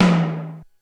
Index of /90_sSampleCDs/300 Drum Machines/Korg DSS-1/Drums03/01
HiTom.wav